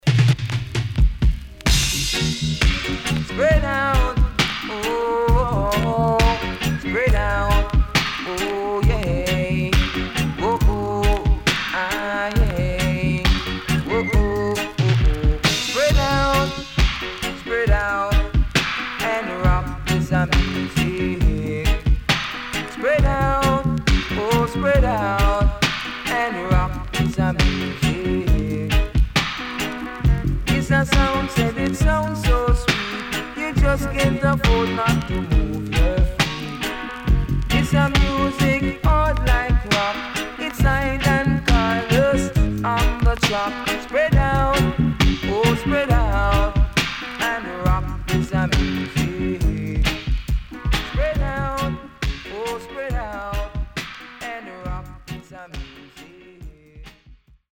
HOME > Back Order [DANCEHALL DISCO45]
SIDE A:全体的にチリノイズがあり、少しプチノイズ入ります。